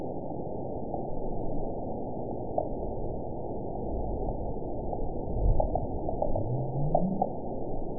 event 922381 date 12/30/24 time 05:16:51 GMT (1 year ago) score 9.63 location TSS-AB03 detected by nrw target species NRW annotations +NRW Spectrogram: Frequency (kHz) vs. Time (s) audio not available .wav